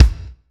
Kick (CHERRY BOMB).wav